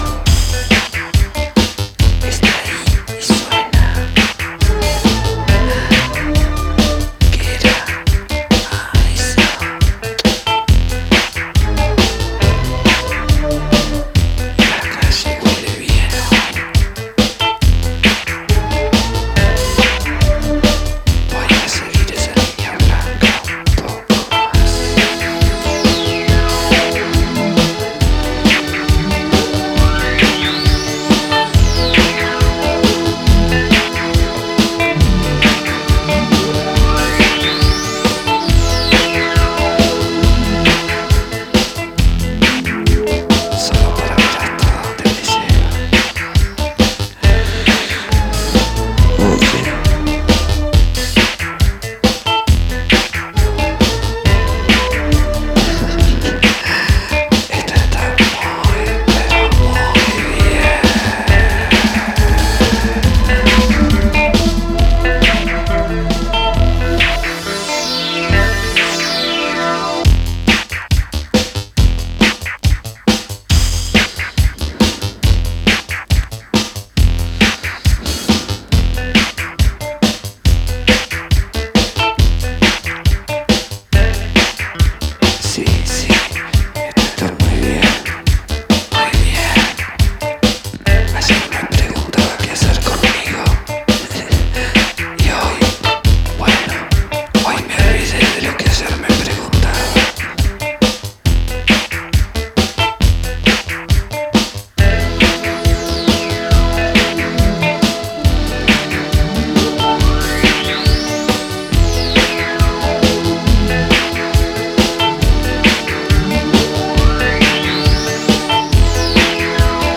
Four groovers of Cosmic, Disco and Italo adventures for all.